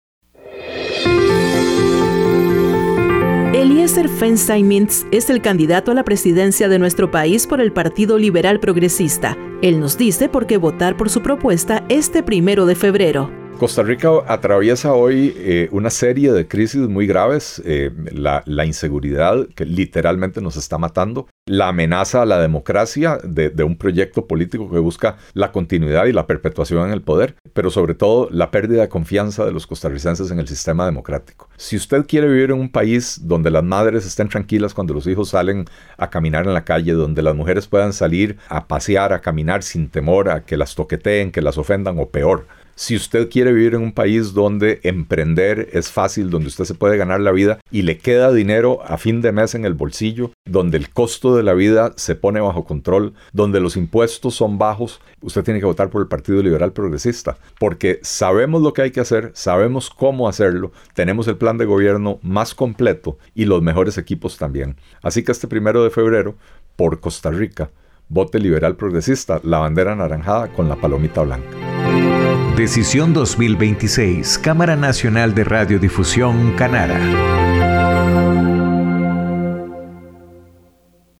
Mensaje de Eliecer Feinzaig Mintz a los oyentes
Eliecer Feinzaig Mintz es el candidato a la presidencia de nuestro país por el Partido Liberal Progresista, él nos dice por qué votar por su propuesta este próximo 1 de febrero.